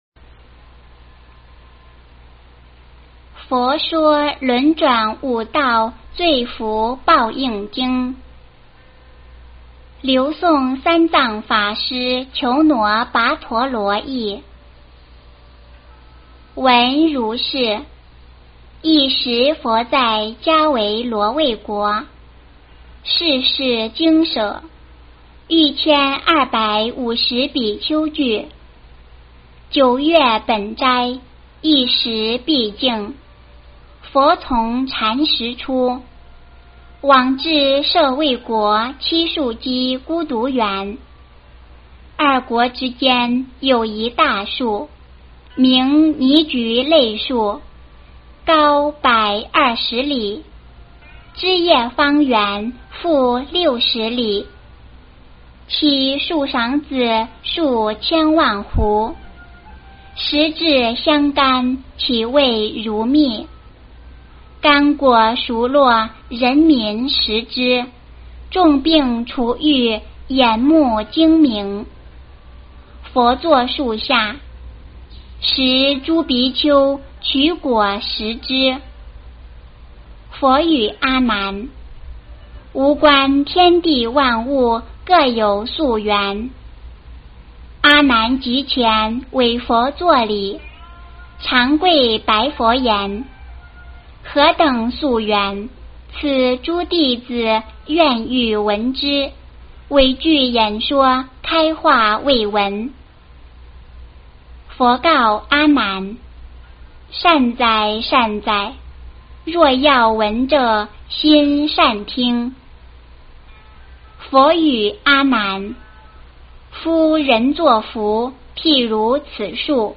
佛说轮转五道罪福报应经 - 诵经 - 云佛论坛